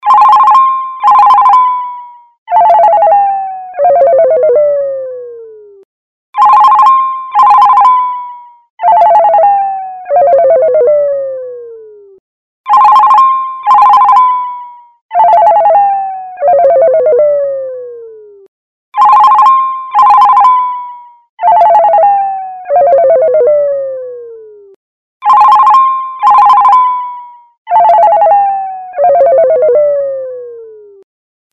جلوه های صوتی
دانلود آهنگ موبایل 11 از افکت صوتی اشیاء
برچسب: دانلود آهنگ های افکت صوتی اشیاء دانلود آلبوم مجموعه زنگ موبایل برای گوشی ها از افکت صوتی اشیاء